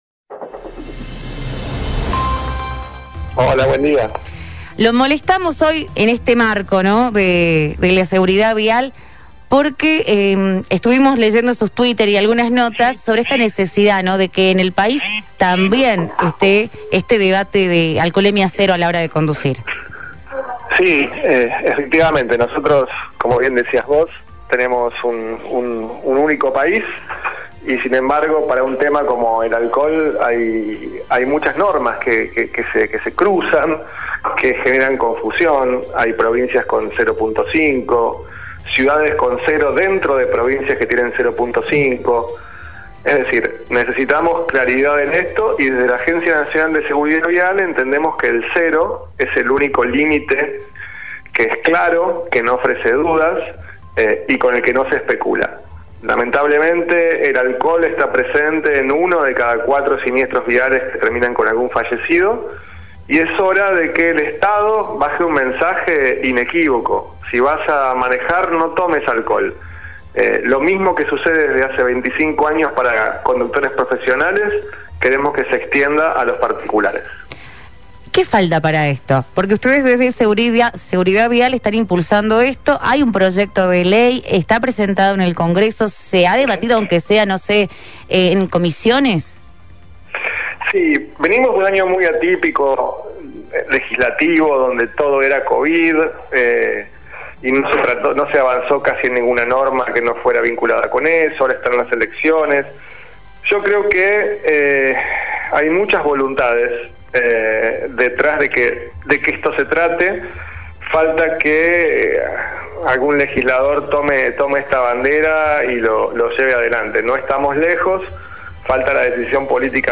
En el podcast de hoy de todojujuy, El titular de Agencia Nacional de Seguridad Vial (ANSV), Pablo Martínez Carignano, destaca la necesidad de una ley de Alcohol Cero que impida que quienes beben manejen un vehículo. Asegura que es posible lograrlo y que hace falta concientización.